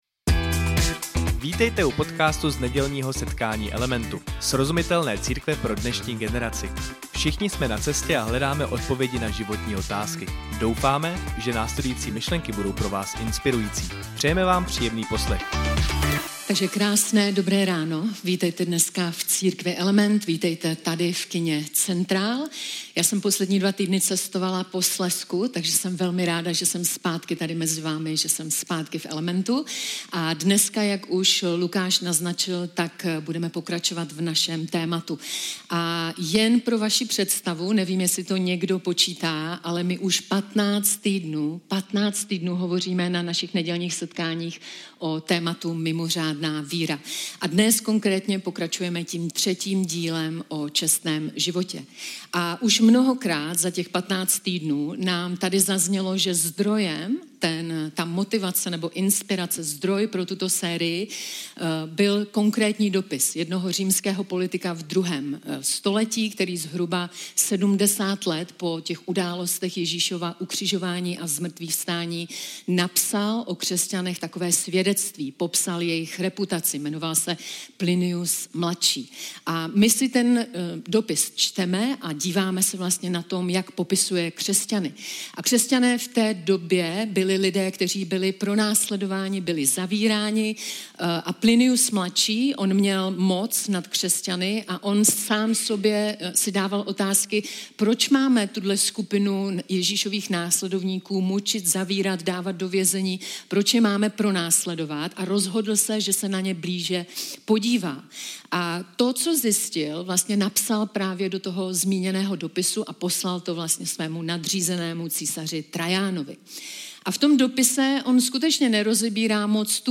Každou neděli přinášíme na svých setkáních relevantní a praktickou přednášku na téma křesťanské víry, většinou tematicky spojenou v sérii několika dalších přednášek.